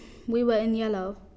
Examples of the most common tunes in the IViE corpus (read sentences)
Falling
Newcastle female
falling-newcastle-female.wav